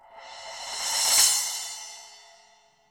Crashes & Cymbals
Shady Crash.wav